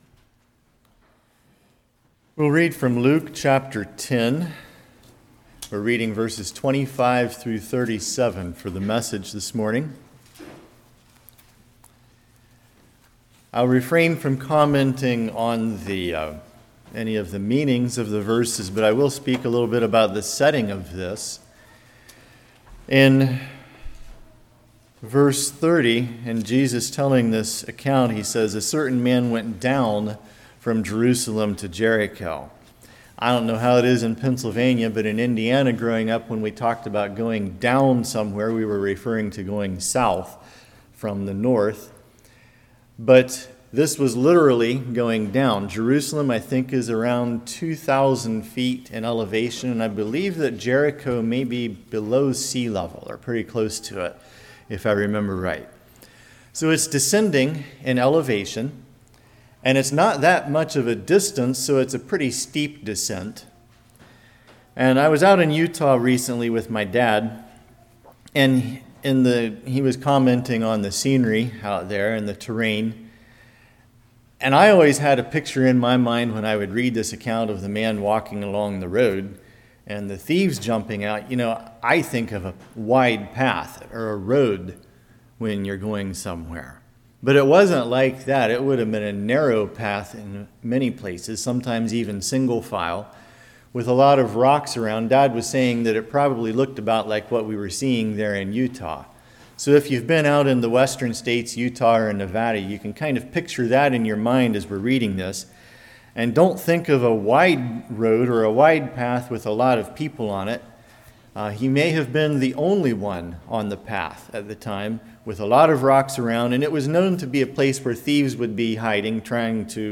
Luke 10:25-37 Service Type: Morning Love God and Neighbor Action proves love for God Go